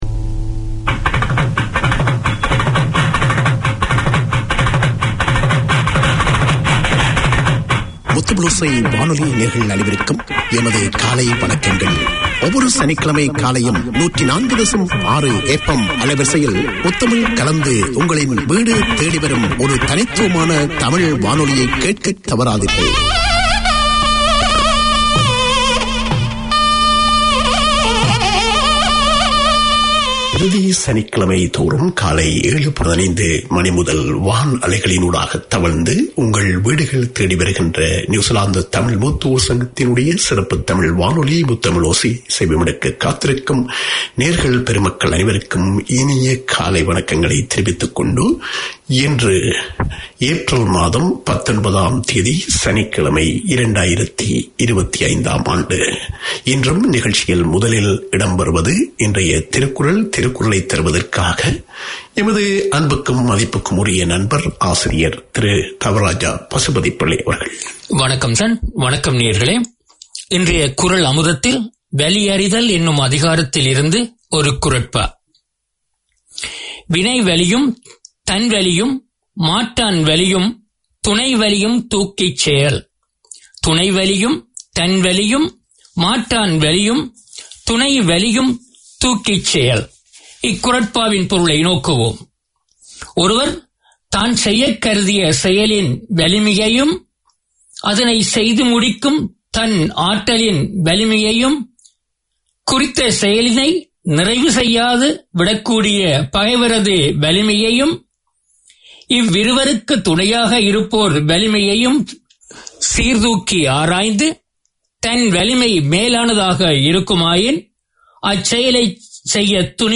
Tamil seniors are up early every Saturday to hear their half hour of radio. On air is a wealth of interviews, music, news, community news, health information, drama and literature.